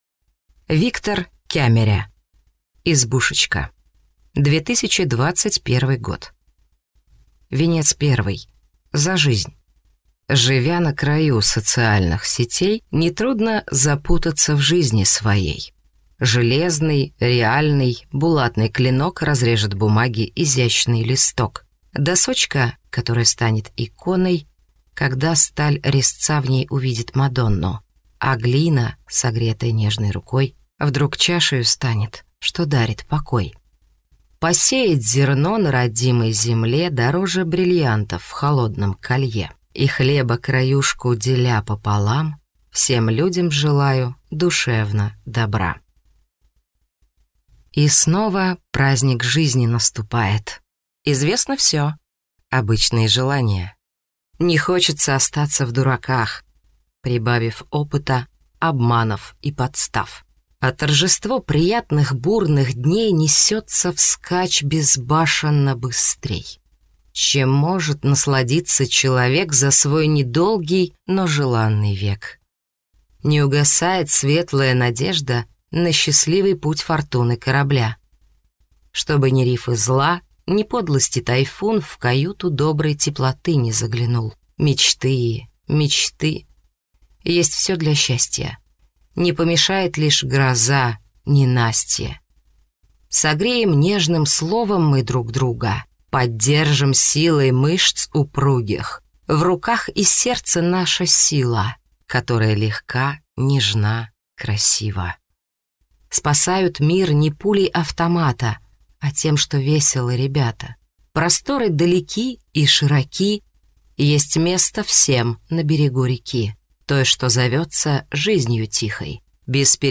Аудиокнига Добротная избушечка | Библиотека аудиокниг